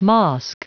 Prononciation du mot mosque en anglais (fichier audio)
Prononciation du mot : mosque